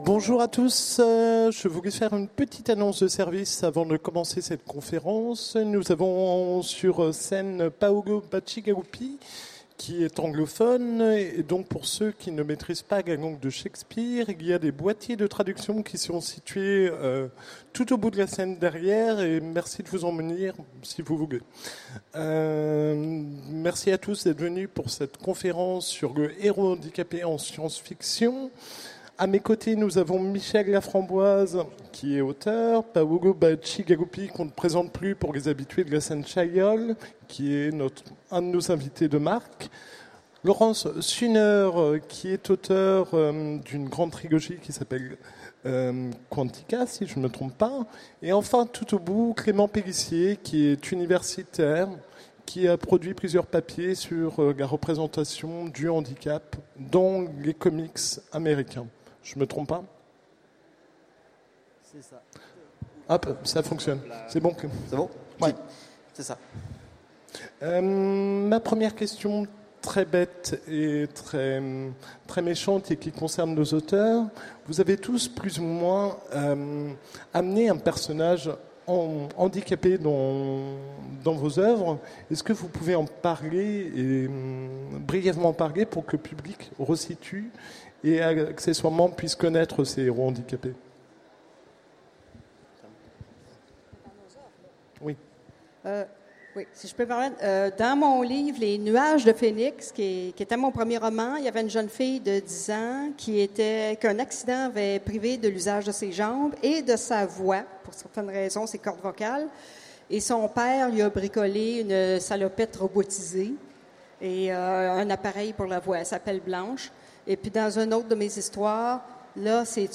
Utopiales 2016 : Conférence Le héros handicapé en science-fiction